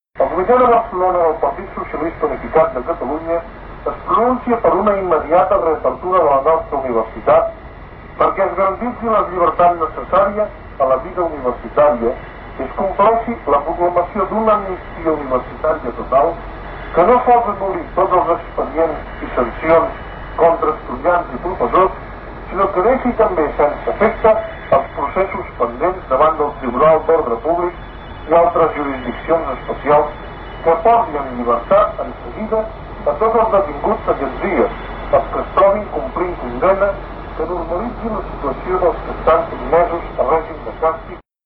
Informatiu
Posem aquesta emissora dins de la tipologia pública internacional perquè en aquell any transmetia des de les instal·lacions de Ràdio Bucarest de Romania.